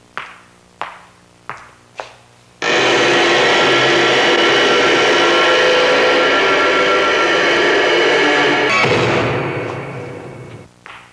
Opening Cell Door Sound Effect
Download a high-quality opening cell door sound effect.
opening-cell-door.mp3